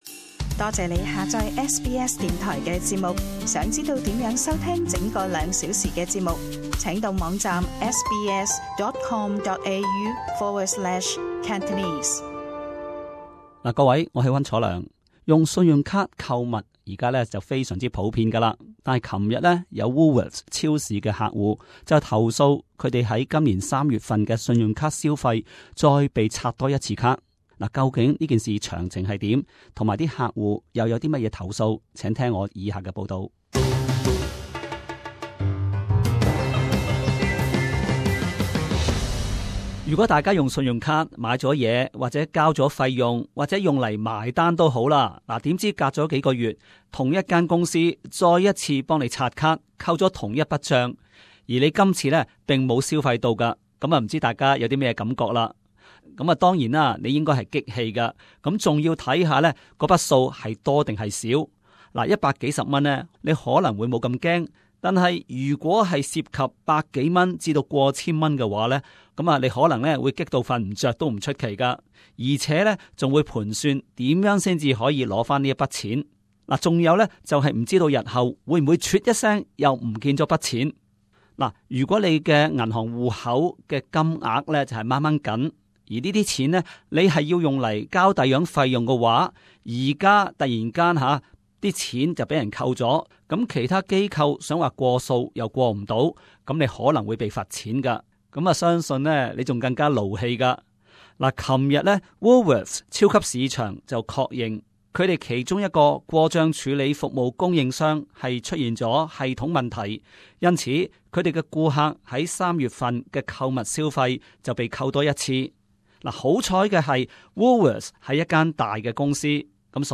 【时事报导】 Woolworths 超市客户投诉无端再被扣数